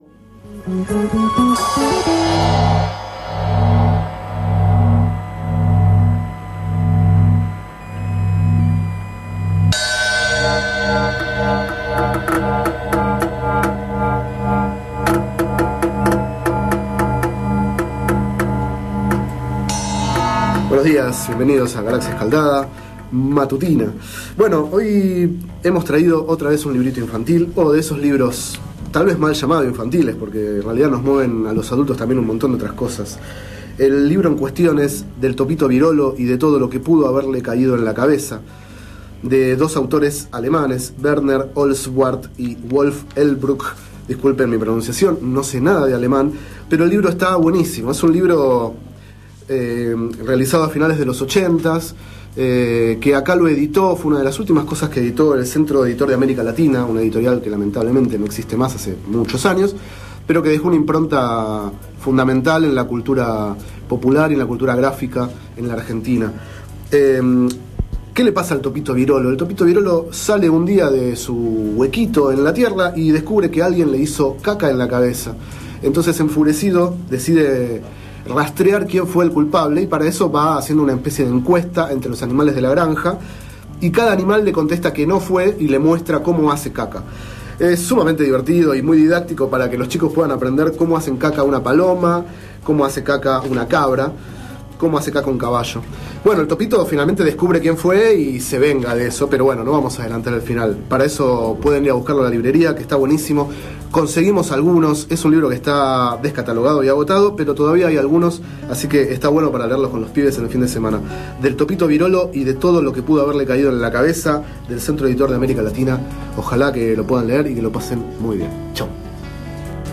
Este es el 10º micro radial, emitido en el programa Enredados, de la Red de Cultura de Boedo, por FMBoedo, realizado el 7 de mayo de 2011, sobre el libro Del Topito Birolo y de todo lo que pudo haberle caído en la cabeza, de Werner Holzwarth y Wolf Erlbruch.